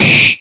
Damage1.ogg